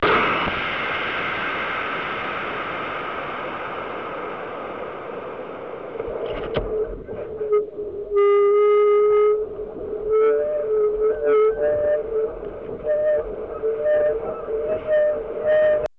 L'enregistrement du son était lui aussi réalisé à l'aide d'un ISD, dont l'entrée était reliée à un microphone à électrets.
Son du vol
Remarquez que l'on entend un claquement au bout de 6 secondes qui correspond à l'ouverture du parachute. Les sifflements à la fin sont dus au fait que le parachute est mal déployé derrière la fusée.